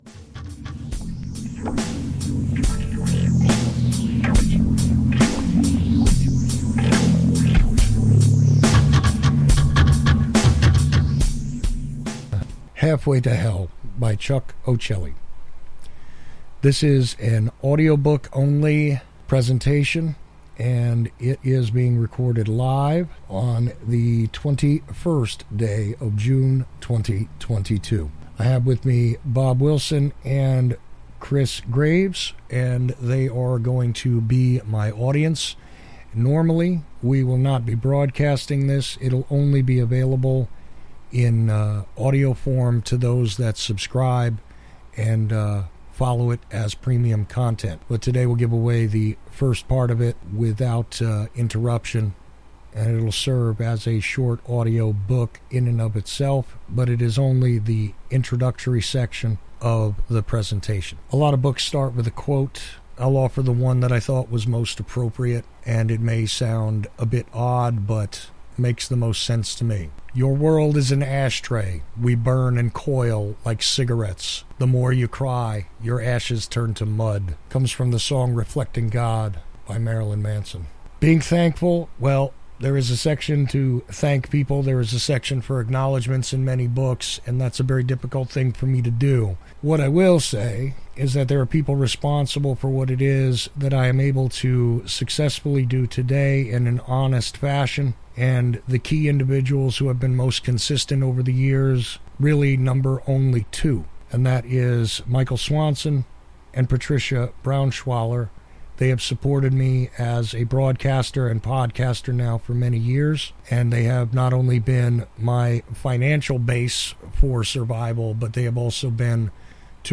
The Free Issue from our New Audiobook offerings